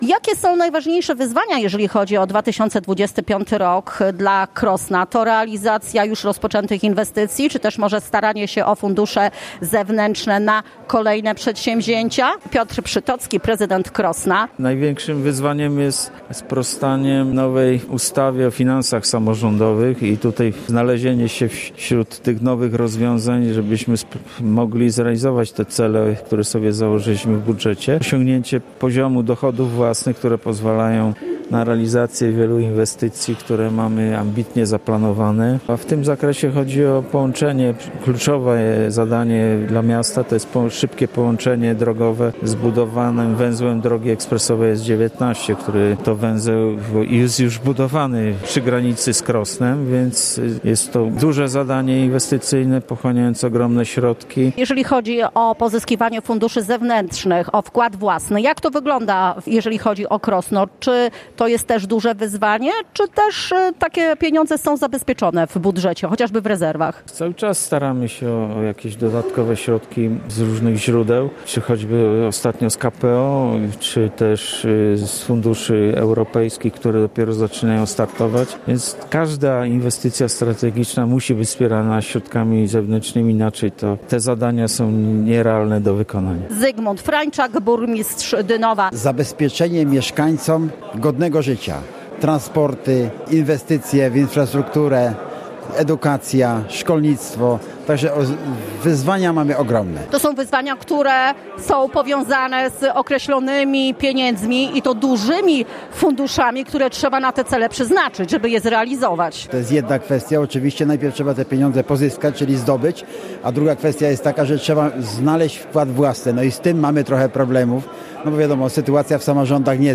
Prawie 300 podkarpackich samorządowców wzięło udział w spotkaniu opłatkowym w Filharmonii w Rzeszowie.
Marszałek województwa Władysław Ortyl powiedział, że podkarpackie samorządy doskonale sobie radzą z pozyskiwaniem funduszy unijnych.